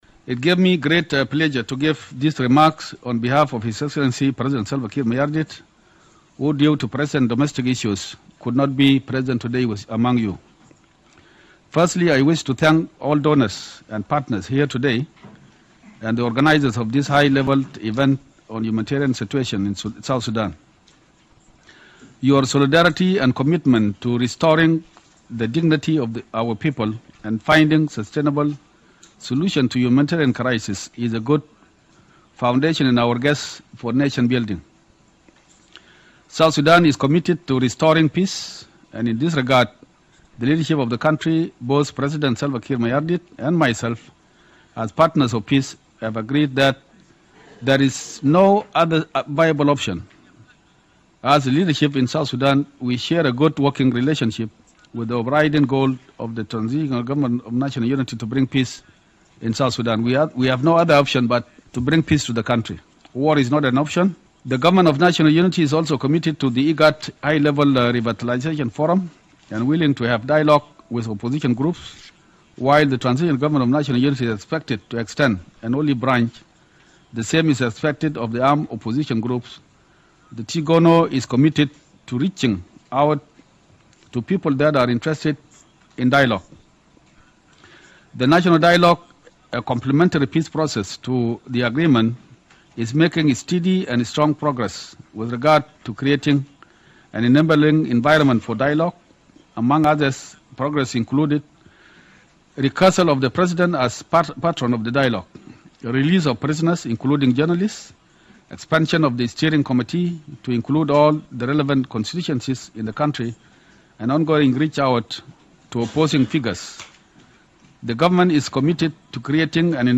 FVP Taban Deng Gai's statement at UN High-Level humanitarian event on South Sudan
First Vice President Taban Deng Gai has told a High-Level humanitarian event at the UN General Assembly in New York that “war is not an option” and reiterated the government’s commitment to ending the conflict.